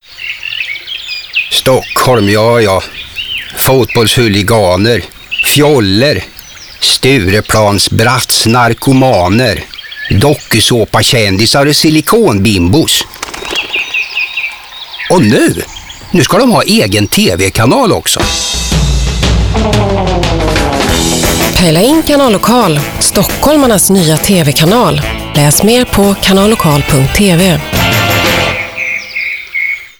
Giant Dwarfs första uppdrag 2006 var en radiokampanj för den nyetablerade TV-kanalen Kanal Lokal Stockholm.